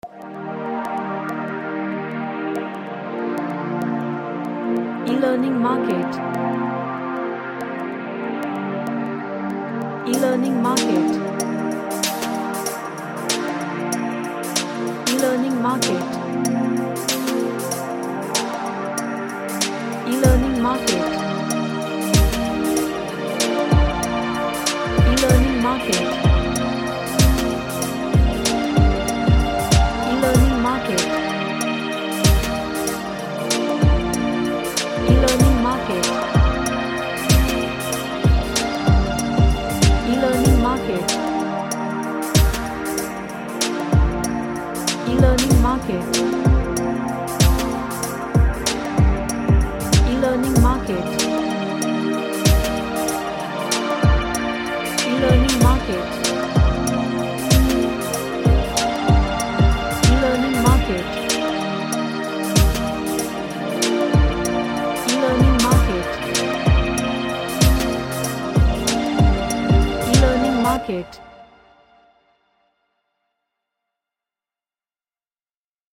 An Ambient track with lots of pads and ambience.
Relaxation / Meditation